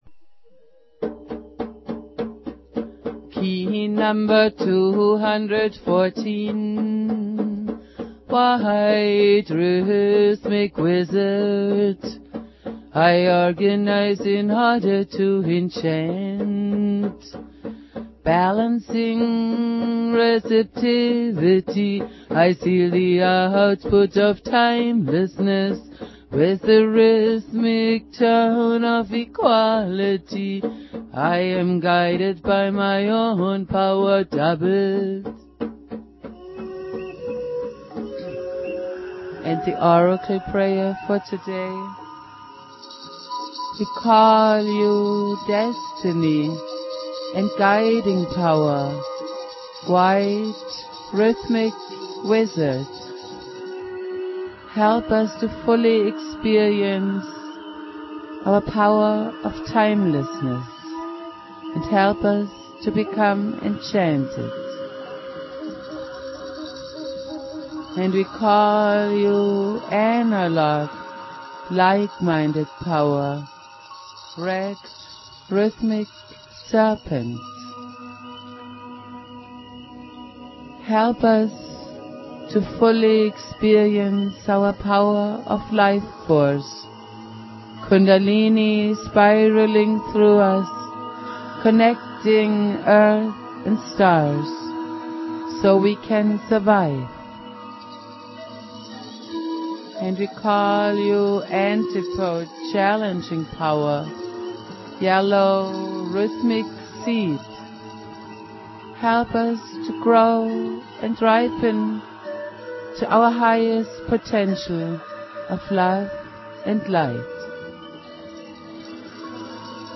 Prayer
flute
Jose's spirit and teachings go on Jose Argüelles playing flute.